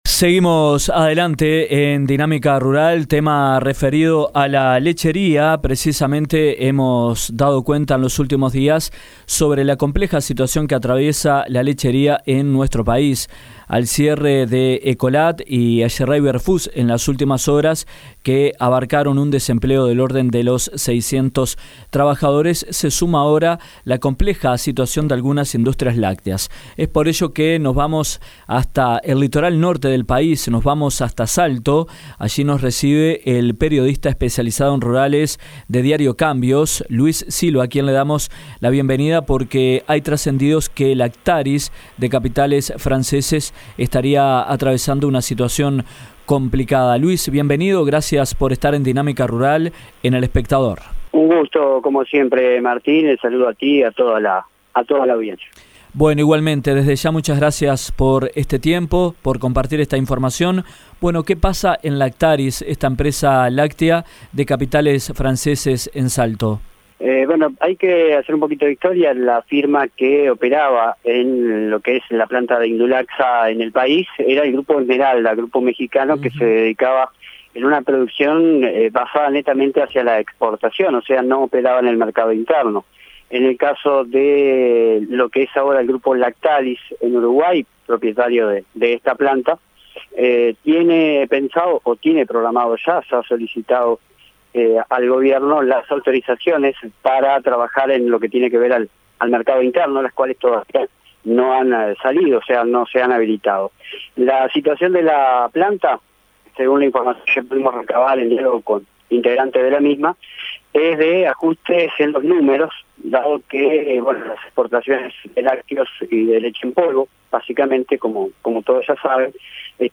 El presidente del INALE, Dr. Ricardo De Izaguirre, en diálogo con Dinámica Rural comentó los avances sobre las posibles medidas y herramientas financieras para el productor lechero